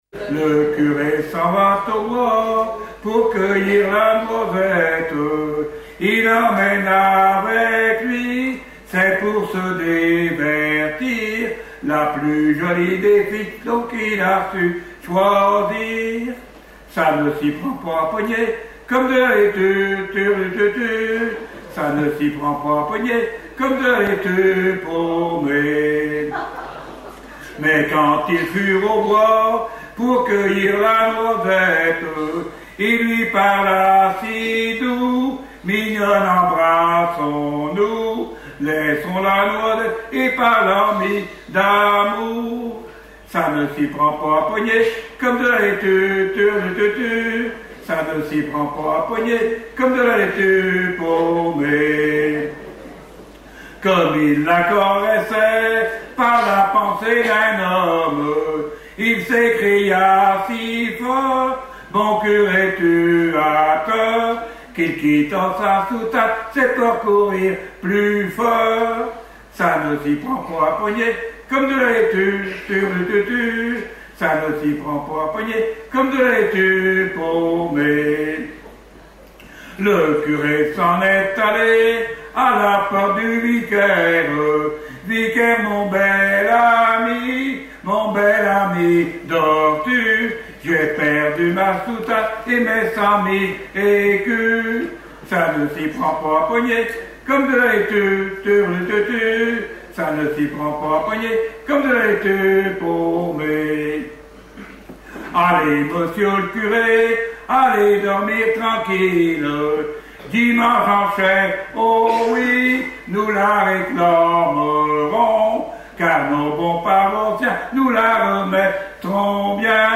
Genre strophique
Festival de la chanson pour Neptune F.M.
Pièce musicale inédite